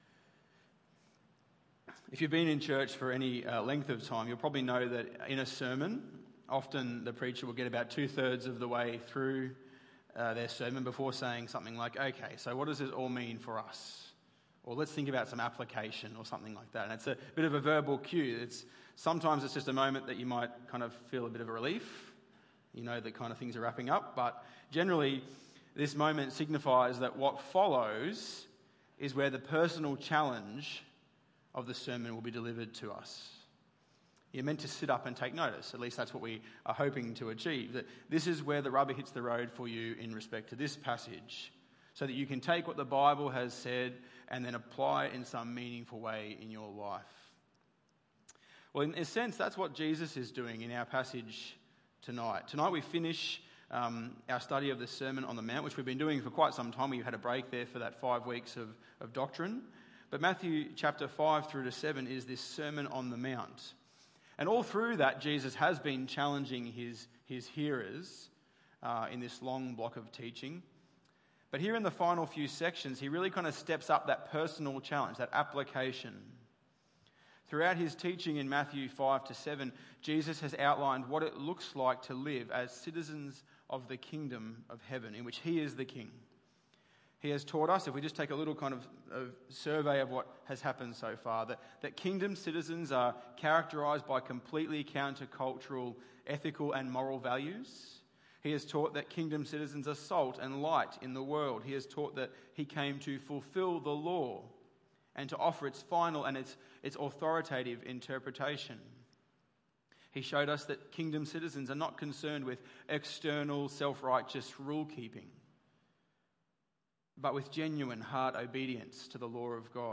Matthew 7:13-29 Tagged with Sunday Evening